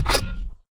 Select Robot 5.wav